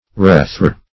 Search Result for " rethor" : The Collaborative International Dictionary of English v.0.48: Rethor \Reth"or\, n. [Cf. F. rh['e]teur.